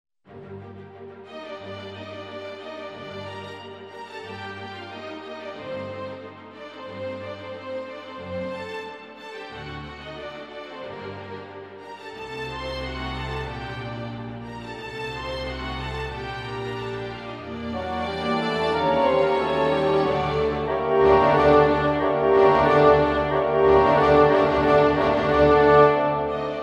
チャララー♪で「交響曲第40番 ト短調 K.550」モーツァルト Symphony No.40 in G minor,K.550 Mozart - チャララー♪でクラシック音楽の一部を表現してみました。